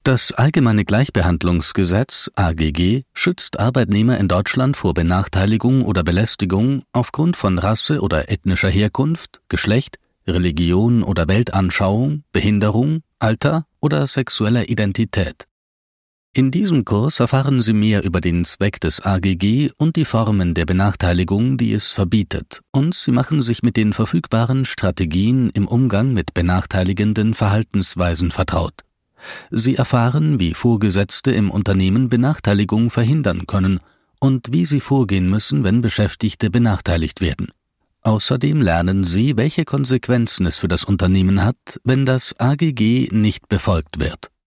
Natural, Versatile, Cool, Reliable, Corporate
E-learning